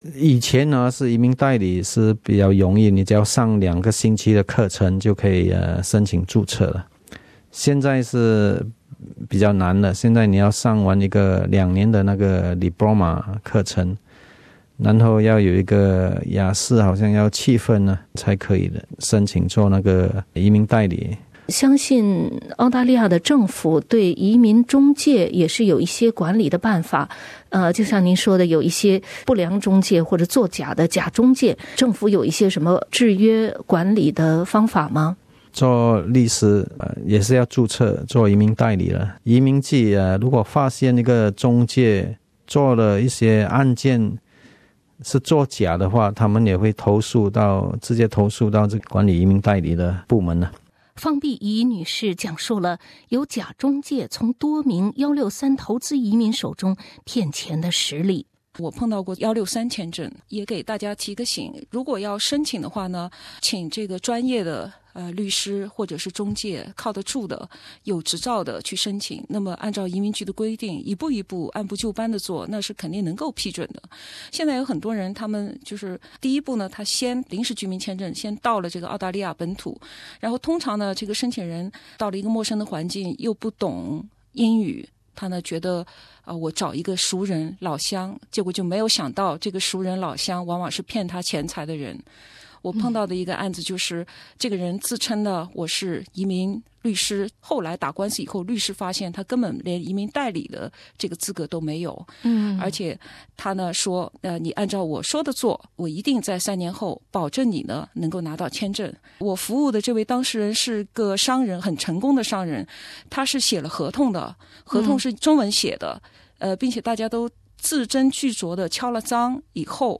从“签证诈骗”的系列访谈中了解到，人们为了获得澳大利亚的居留权用尽了合法和不合法的手段。在一系列地下交易和产业链当中的关键环节是移民中介所起到的作用。